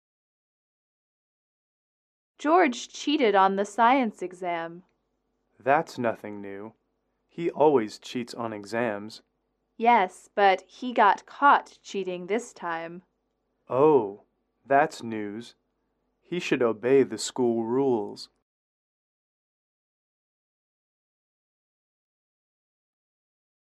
英语口语情景短对话19-4：考试作弊（MP3）